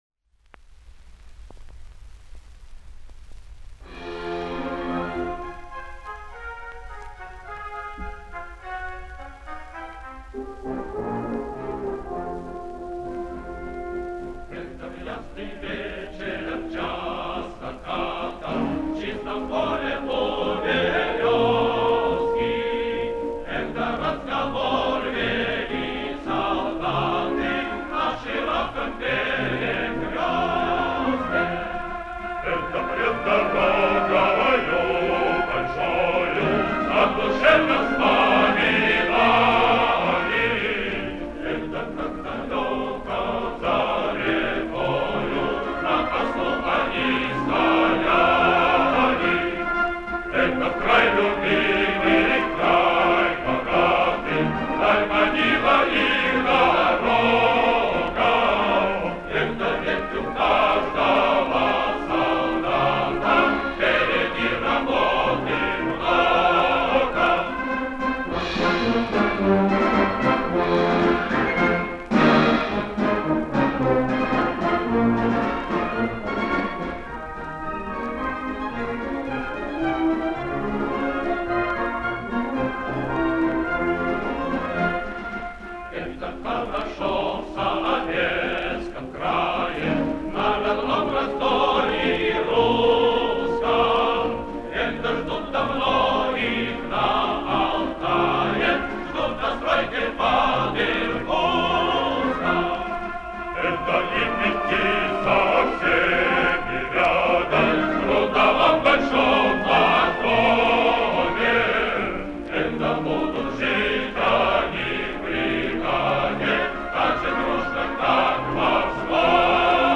духовой оркестр